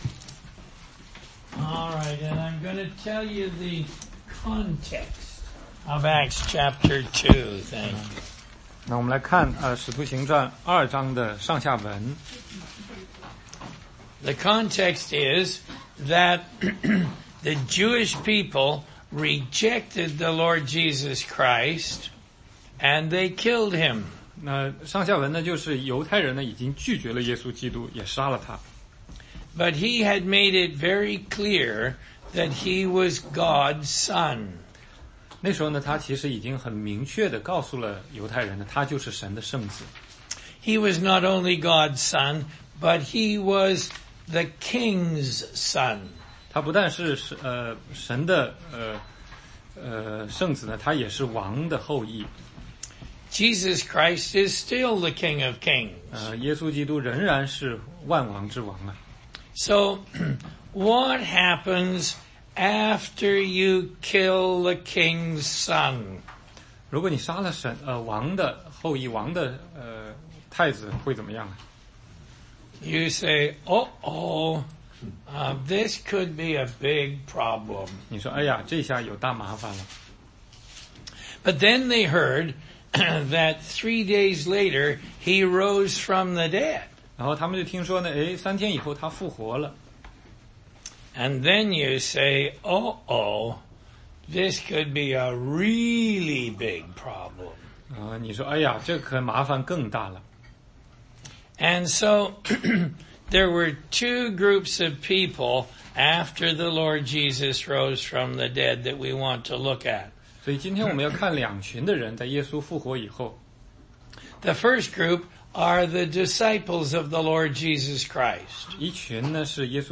16街讲道录音 - 耶稣升天后的故事
答疑课程